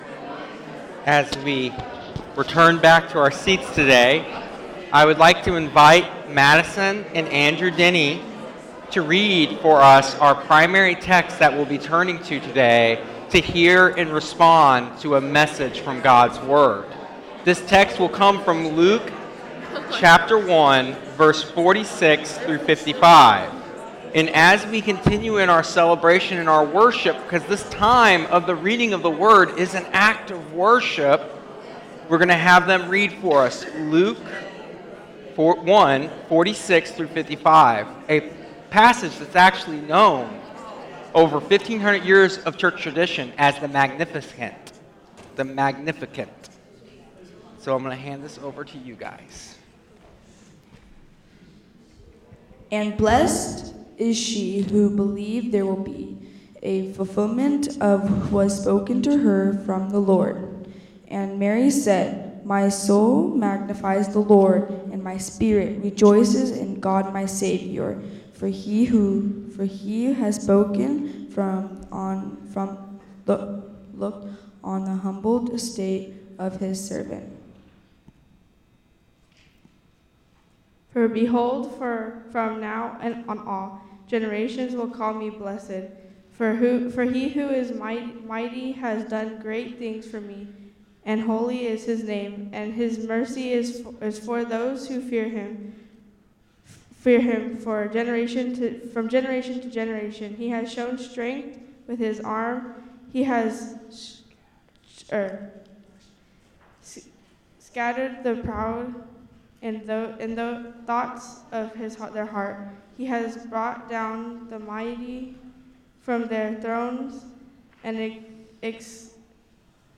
This sermon reflects on the true nature of joy revealed in Jesus’ first coming, drawing from Luke 1:39-45 and Luke […]